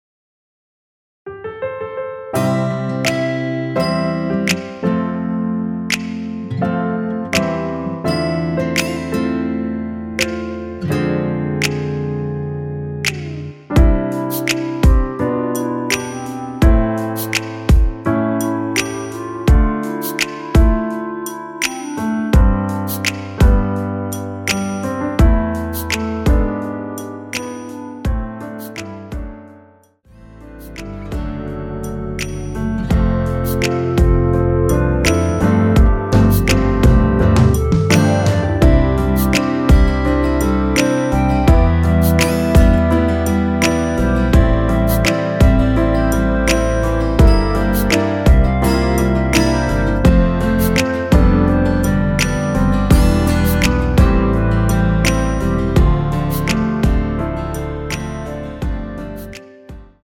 멜로디 MR입니다.
원키에서(-2)내린 멜로디 포함된 MR입니다.
앞부분30초, 뒷부분30초씩 편집해서 올려 드리고 있습니다.
중간에 음이 끈어지고 다시 나오는 이유는